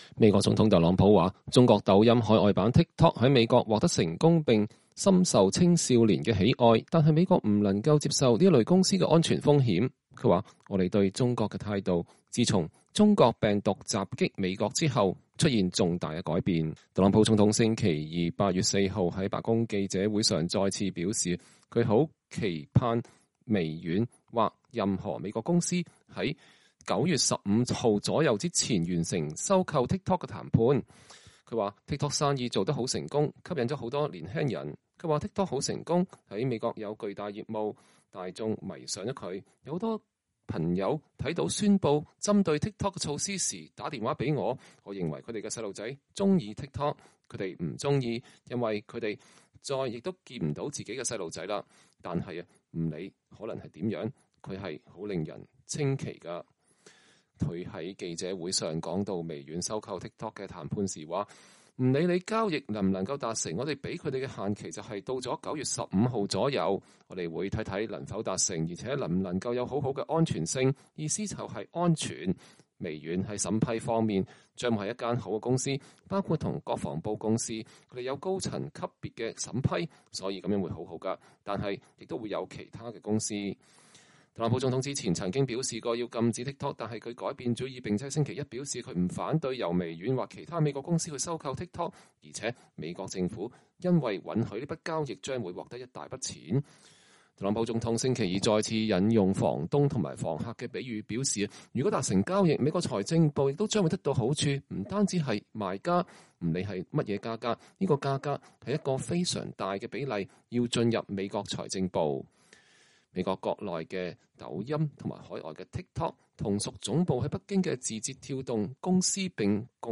特朗普總統在白宮記者會上講話。 (2020年8月4日)